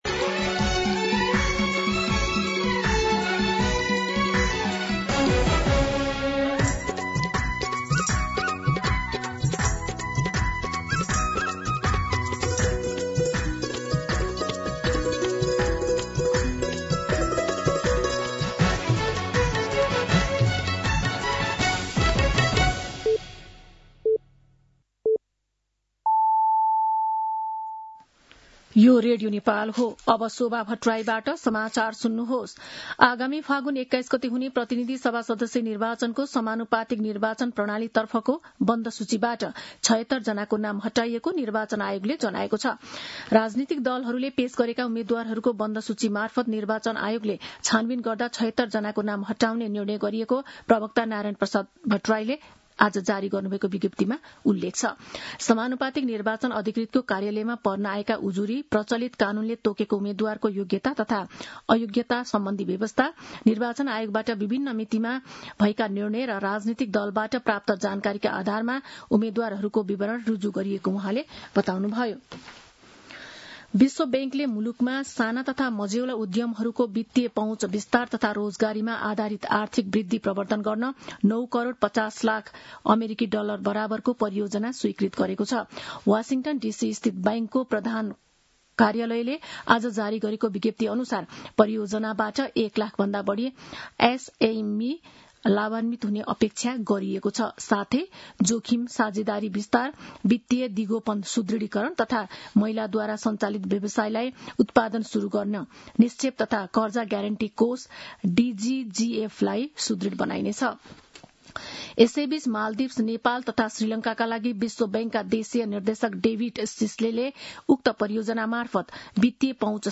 मध्यान्ह १२ बजेको नेपाली समाचार : २० माघ , २०८२